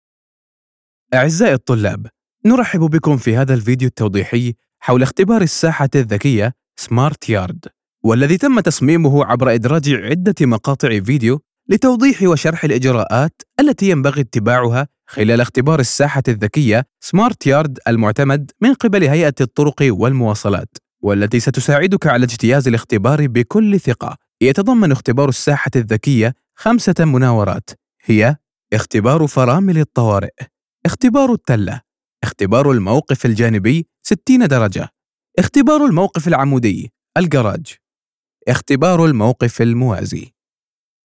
Comercial, Cálida, Empresarial, Profundo, Versátil
E-learning